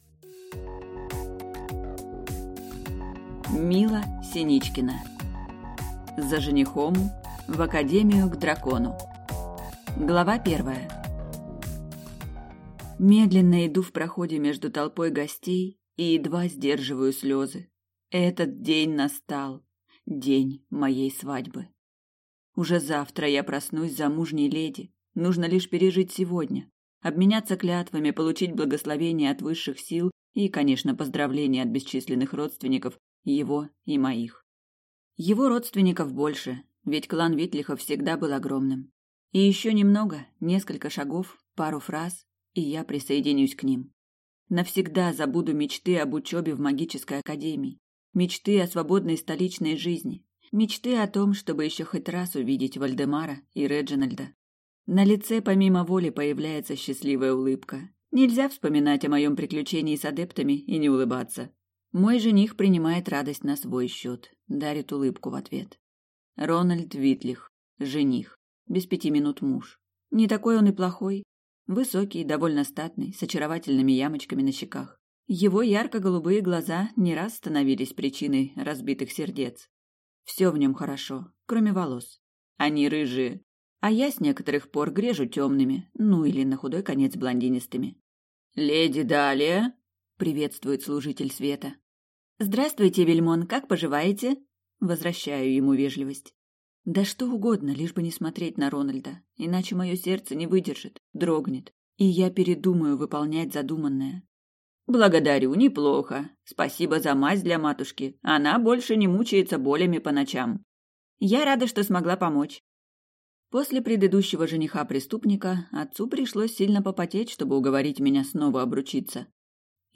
Аудиокнига За женихом… в академию к дракону | Библиотека аудиокниг